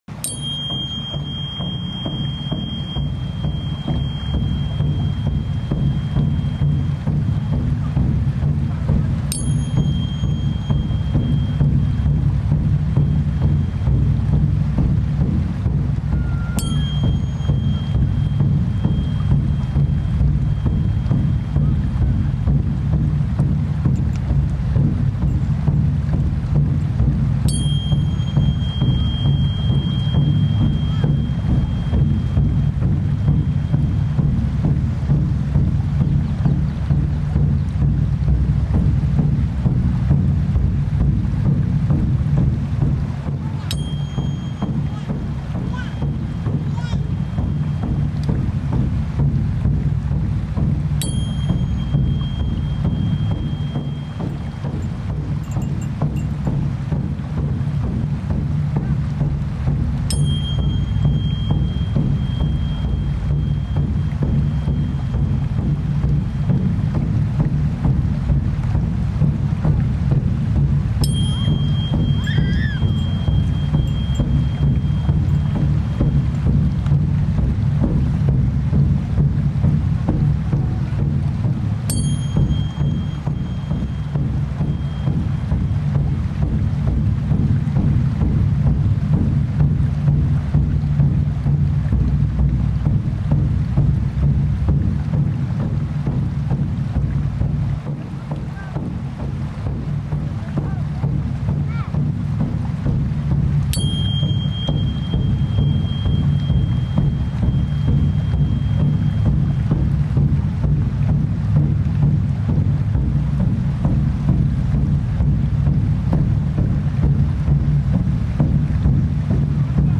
This ceremony for love and thanks to water was held June 5-6, 2009 as part of Grandmother Drums three month LIVE WATER ISRAEL Tour leading up to the LIVE H20 Concert for the Living Waters weekend June 19-21, 2009.